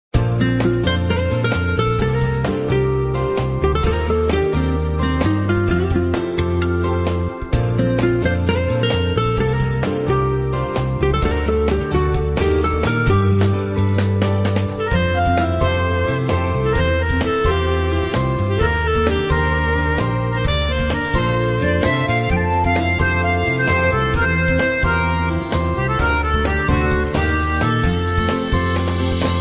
M4R铃声, MP3铃声, 个性铃声 33 首发日期：2018-05-14 05:55 星期一